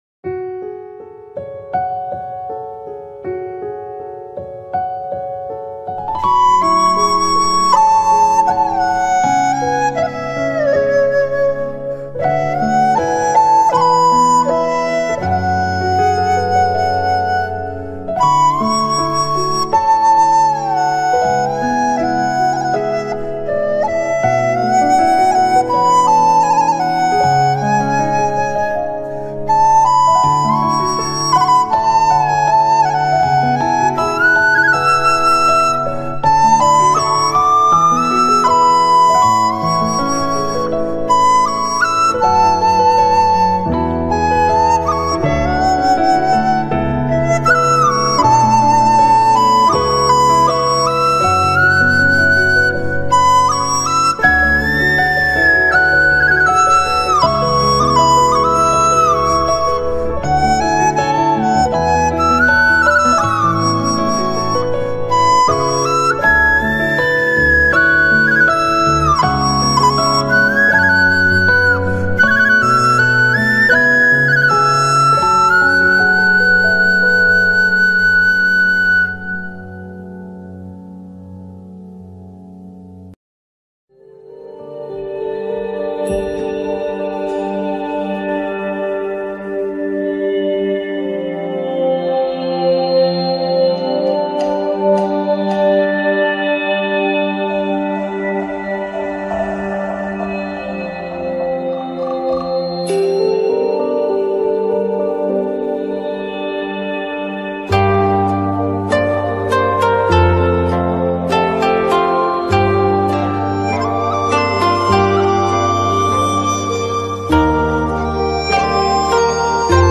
Romantic-Chinese-Flute-And-Pipa-Music.mp3